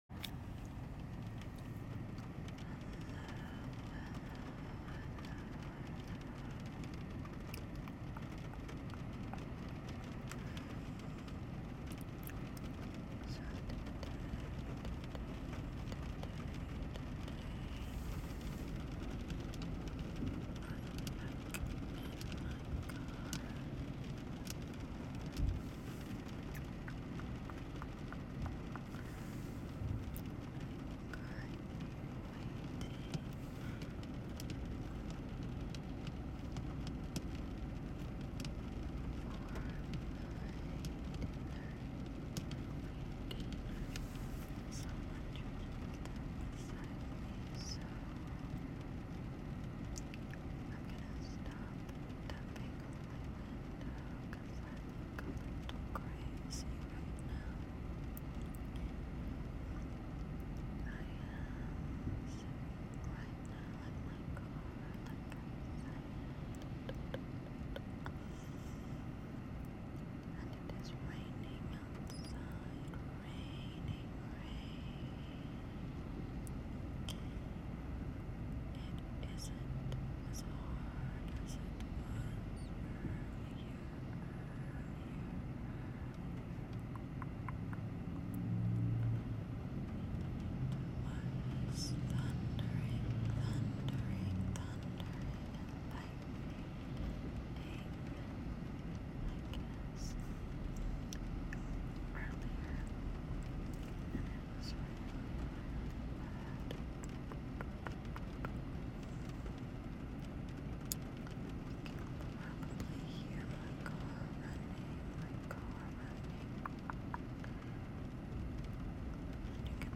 some more lofi slow sleepy sound effects free download
some more lofi slow sleepy whispers for ya! this time with some nice rain 🌧